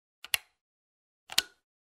Звуки розетки, выключателей
Настенный выключатель света